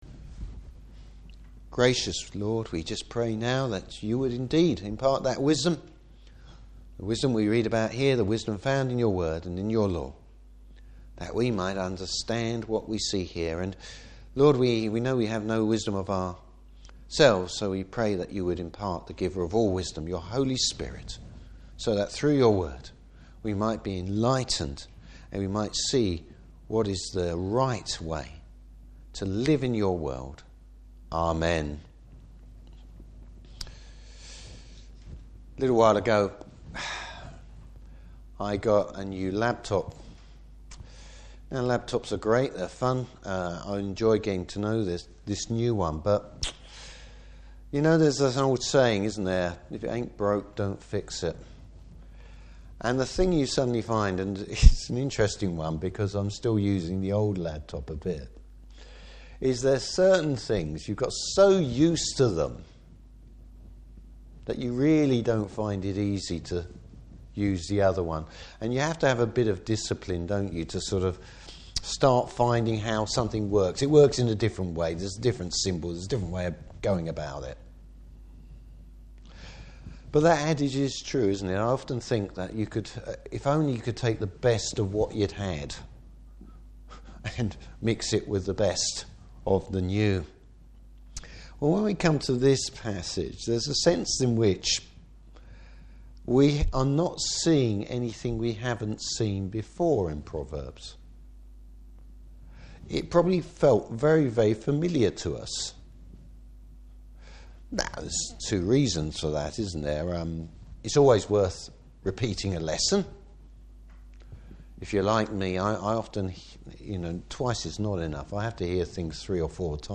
Service Type: Morning Service Solomon recaps much of what we have looked at so far.